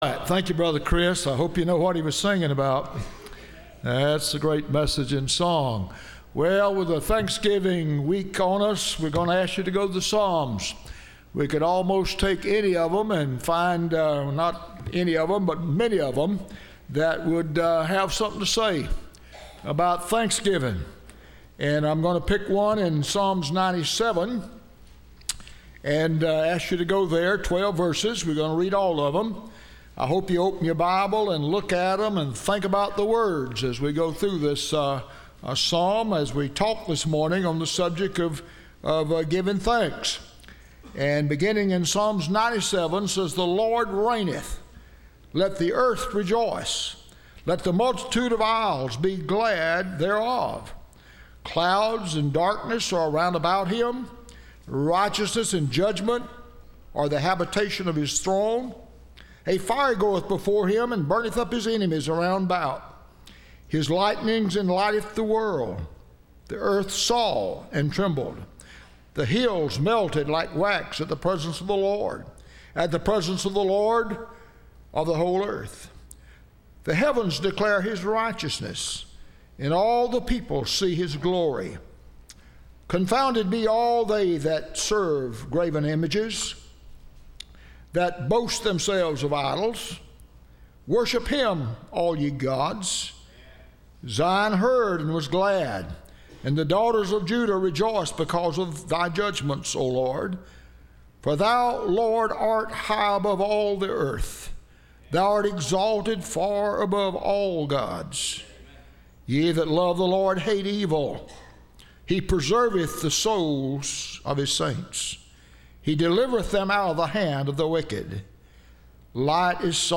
Listen to Message
Service Type: Sunday Morning